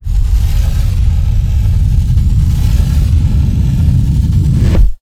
Puerta.wav